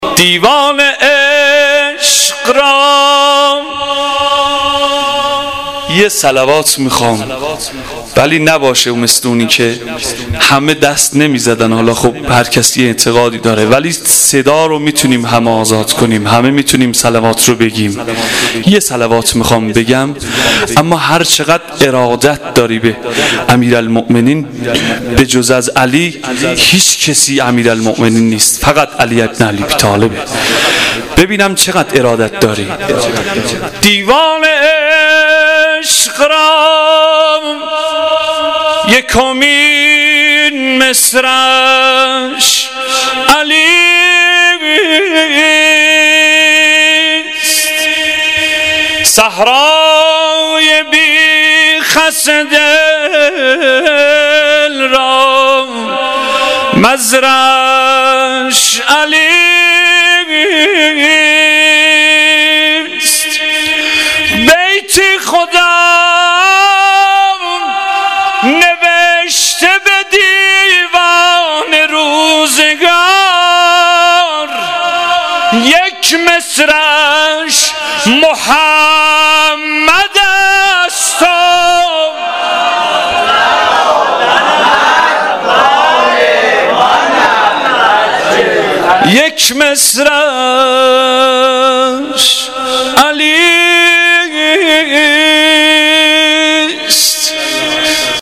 مدح _ شب میلاد امام علی (علیه السلام)